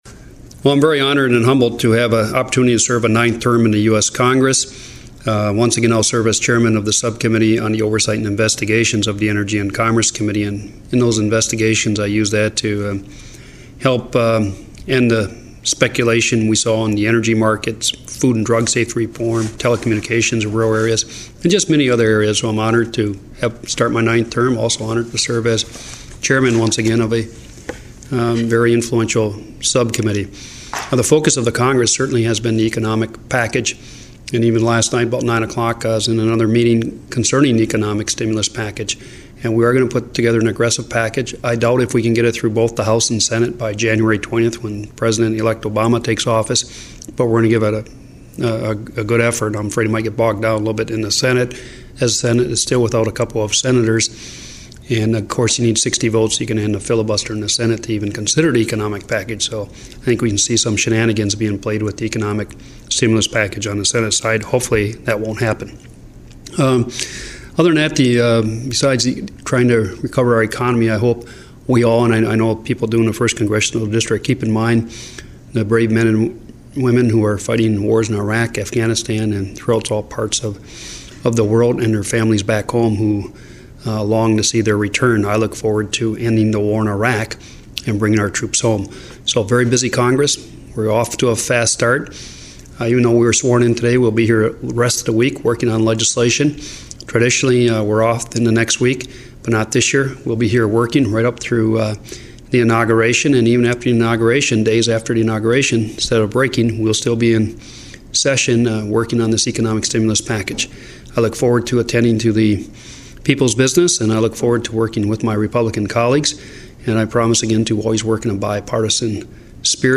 Congressman Bart Stupak – Comments on being sworn in for his ninth term in Congress.